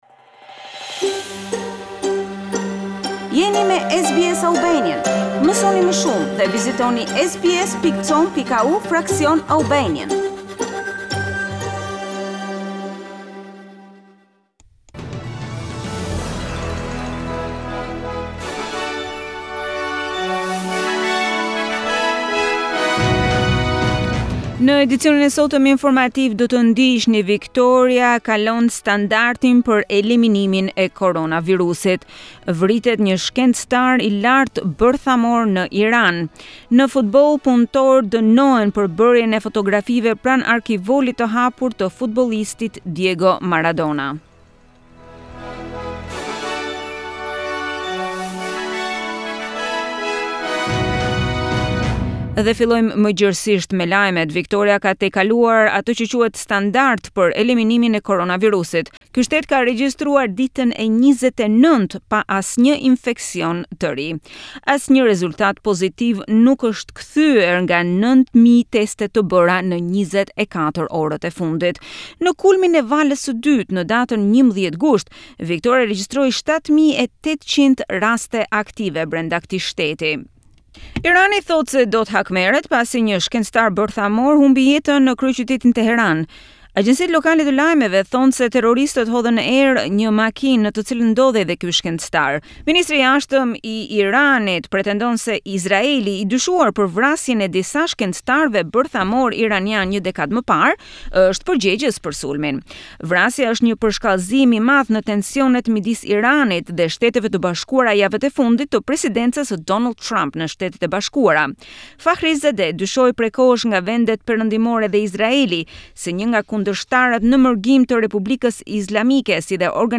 News Bulletin in Albanian 28 November 2020